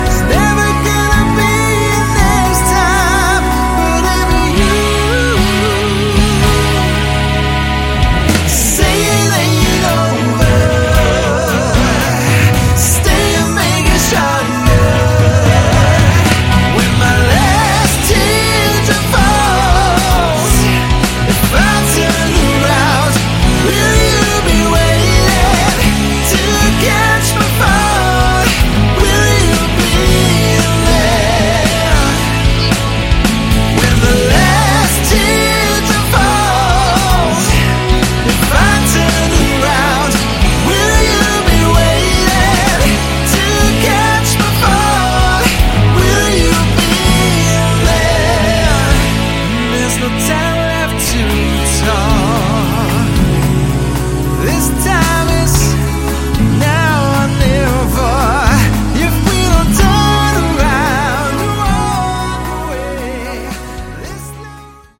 Category: AOR
lead and backing vocals, guitar, bass, drums